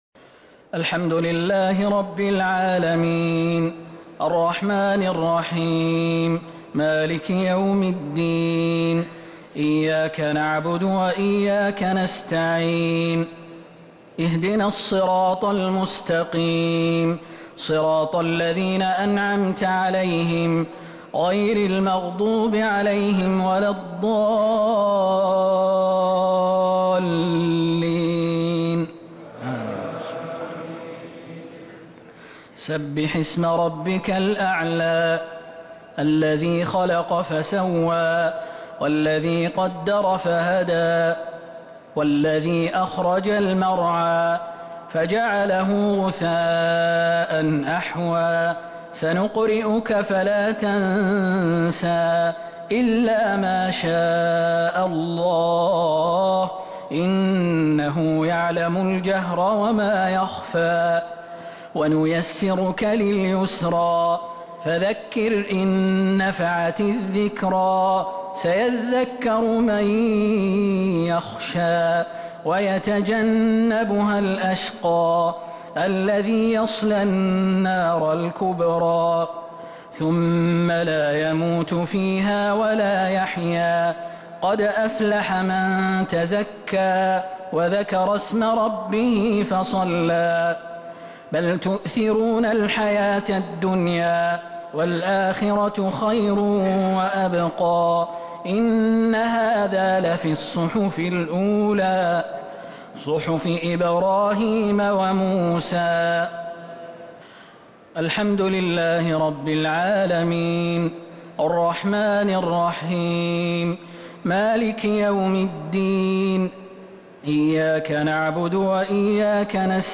صلاة الشفع و الوتر ليلة 3 رمضان 1447هـ | Witr 3rd night Ramadan 1447H > تراويح الحرم النبوي عام 1447 🕌 > التراويح - تلاوات الحرمين